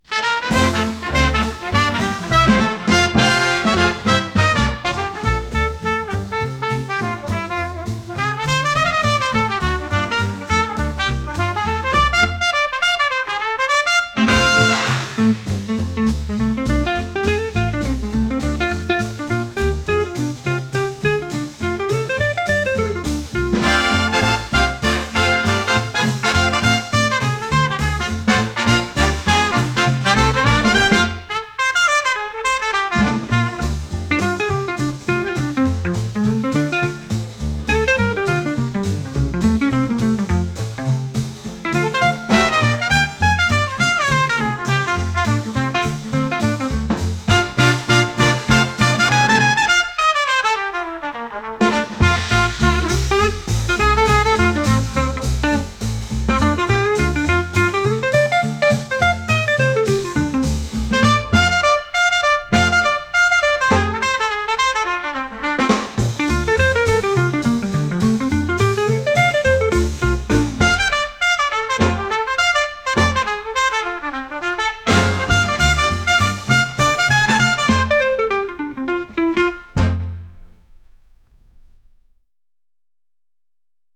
踊れるようなテンポのいいジャズ曲です。